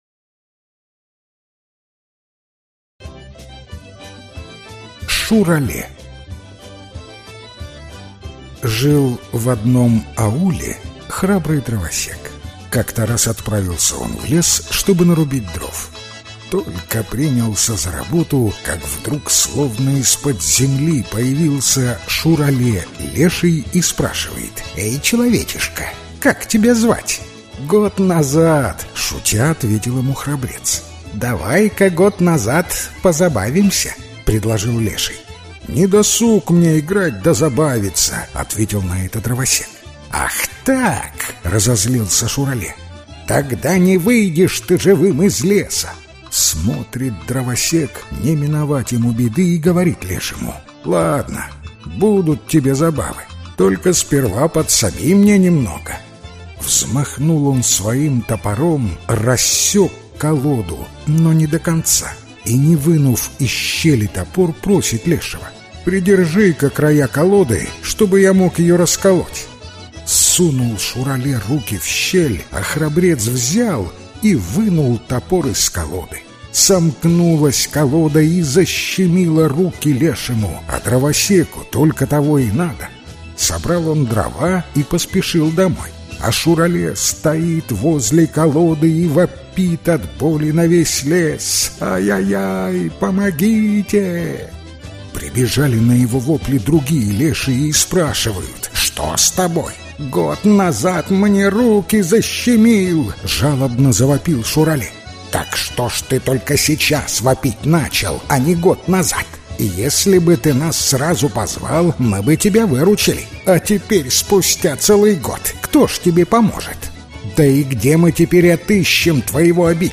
Аудиокнига Волшебные татарские сказки | Библиотека аудиокниг